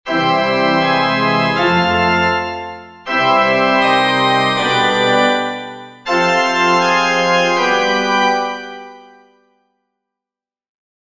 Dagegen drängen chromatisch veränderte Akkordtöne (alterierte Töne) zur Fortschreitung in eine neue Harmonie, der als Prim, Terz oder Quinte der Ton angehört, zu welchem der alterierte Ton leitet:
Auflösung alterierter Akkordtöne